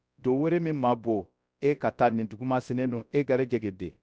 Synthetic_audio_bambara